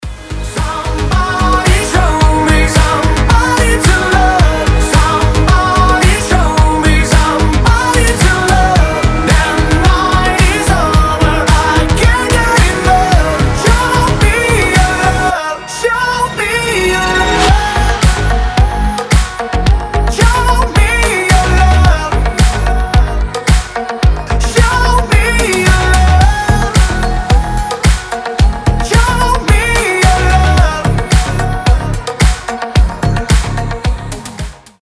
• Качество: 320, Stereo
громкие
красивый мужской голос